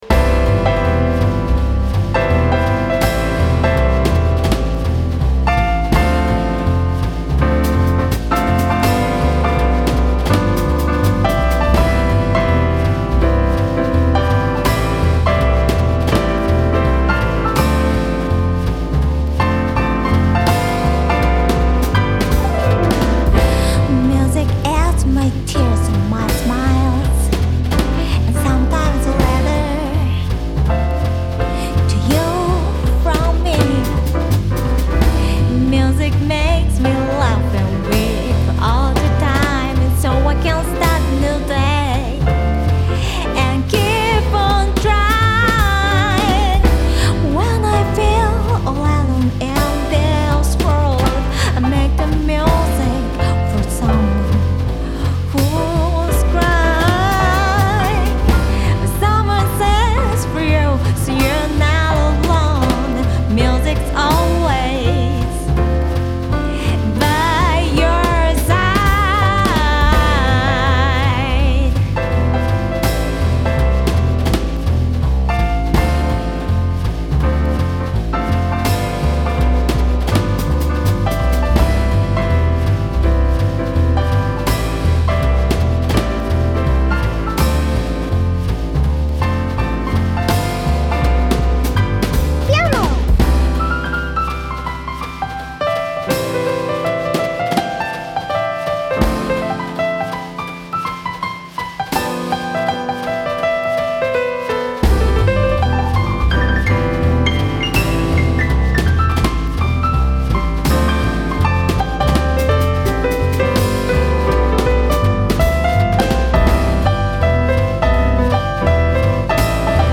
ジャンル(スタイル) JAPANESE / JAZZ / CROSSOVER